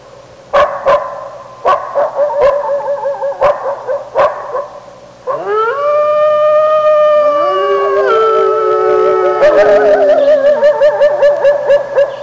Dances With Wolfs Sound Effect